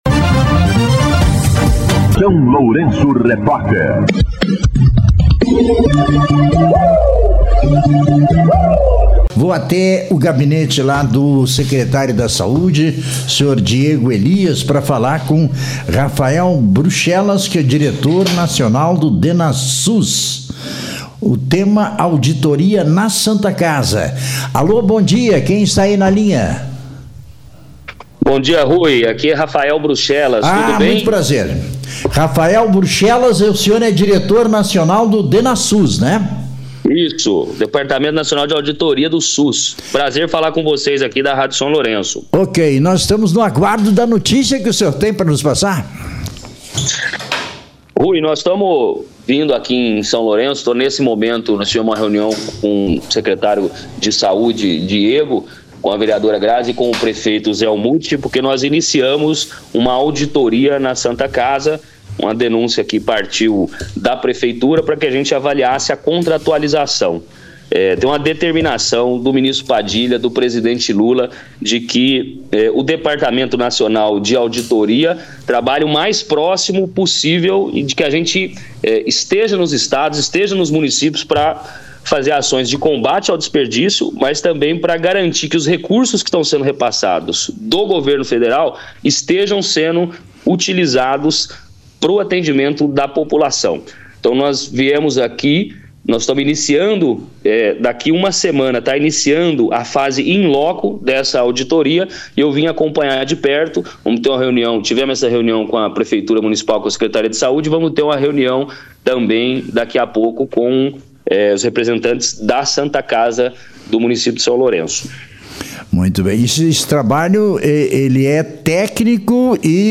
Entrevista com o Diretor Nacional DenaSUS, Rafael Bruxelas